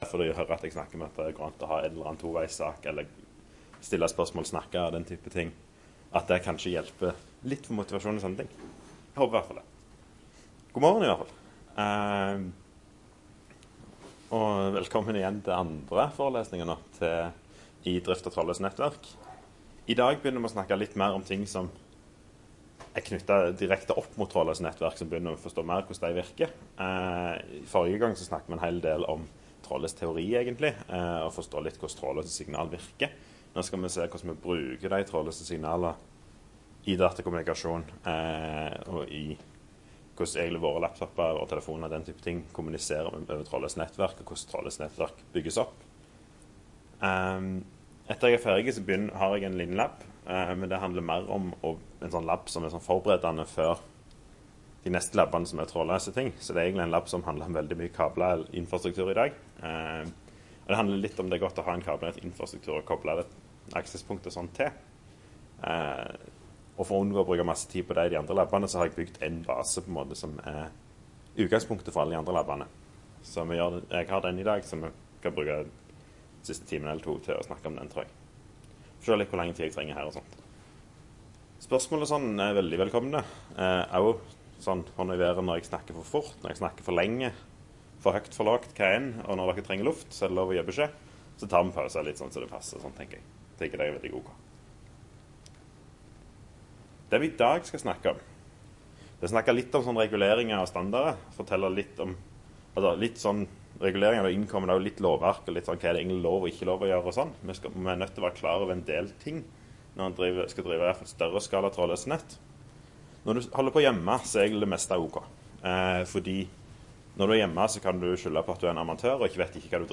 Trådløse Standarder - NTNU Forelesninger på nett
Rom: A146